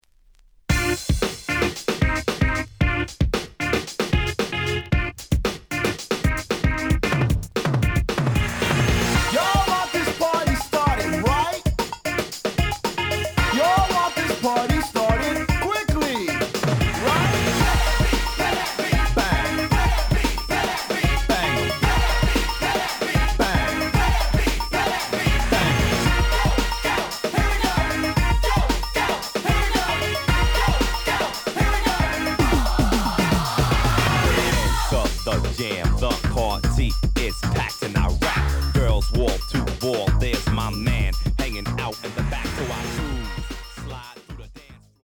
The audio sample is recorded from the actual item.
●Format: 7 inch
●Genre: Hip Hop / R&B